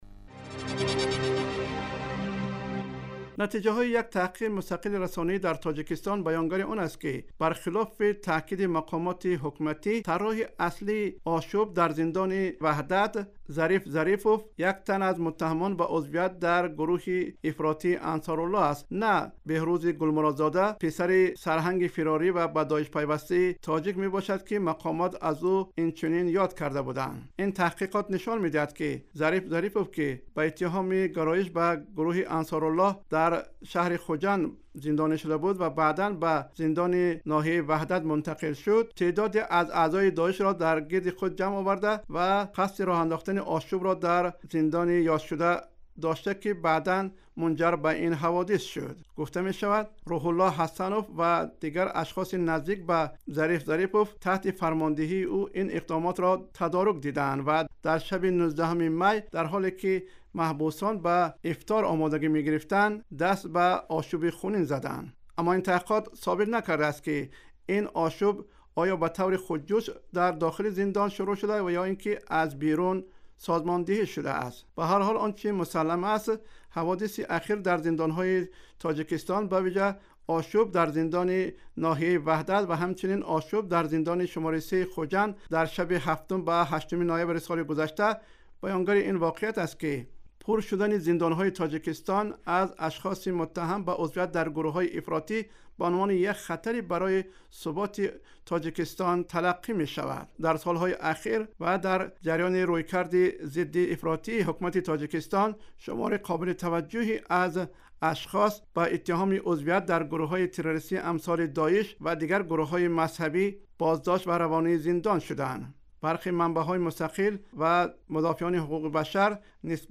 Гузориши вижа. Нигоҳе ба тавоноии амниятии Тоҷикистон дар муқобила бо таҳдидҳои террористӣ